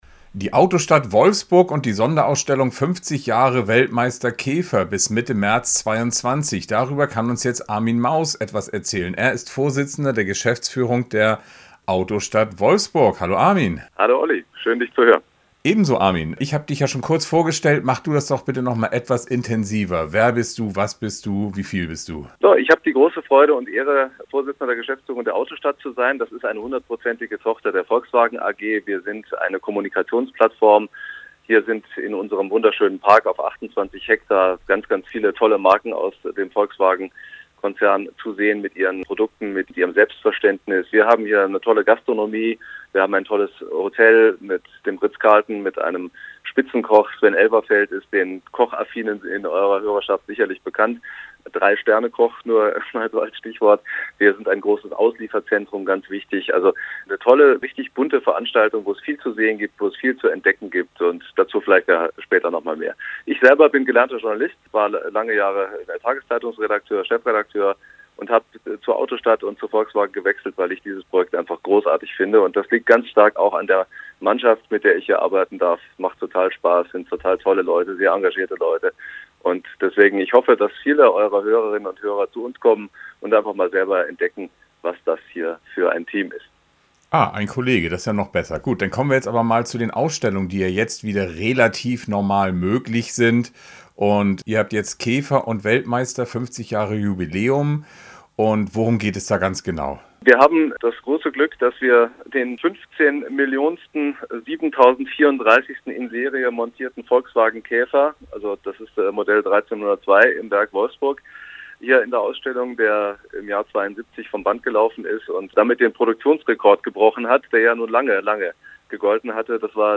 - Interviews & Reportagen | RADIO OLDTIMER